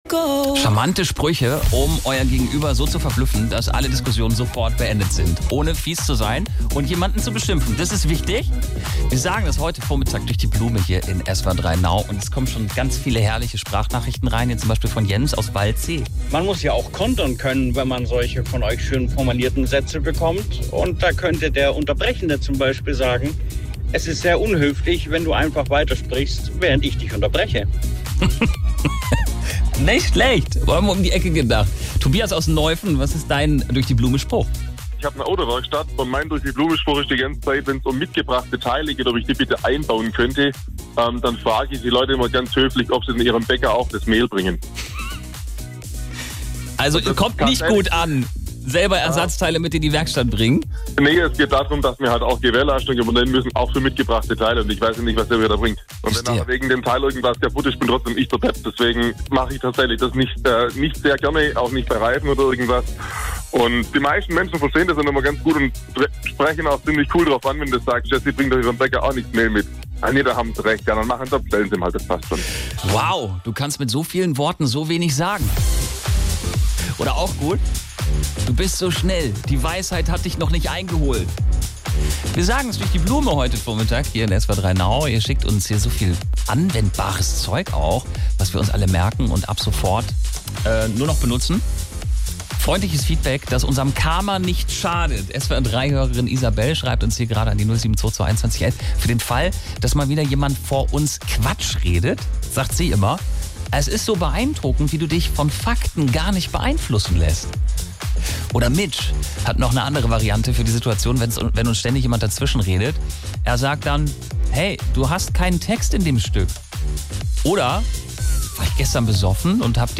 Best-of aus dem Radio: Sprüche, die es durch die Blume sagen